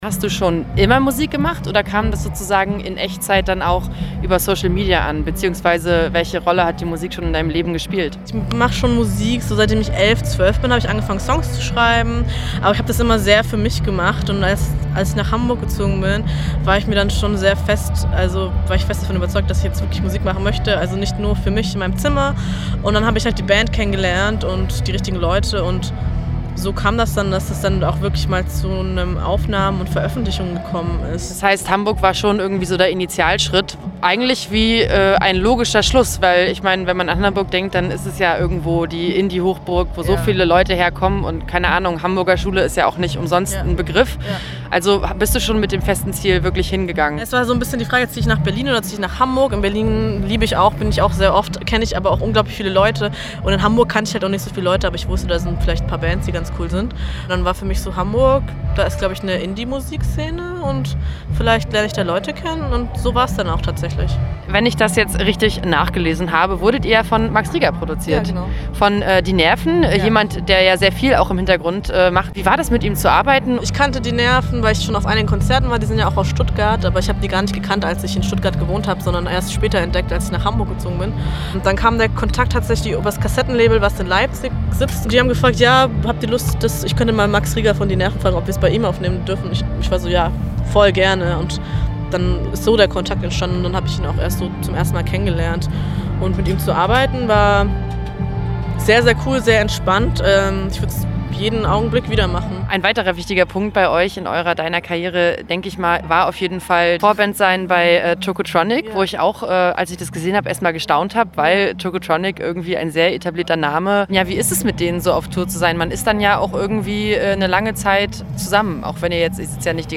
Immergut Festival 2018: Künstlerinterviews
Mittendrin waren auch ein paar unserer Redakteur_innen, die sich Künstler_innen geschnappt und vorm Mikro interviewt haben: